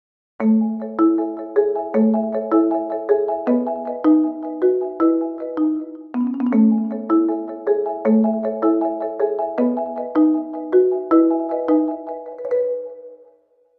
xylophonic